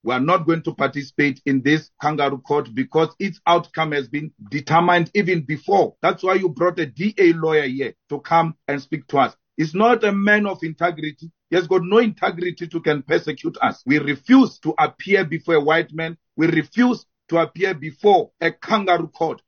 ENG-MalemaOnInitiator.mp3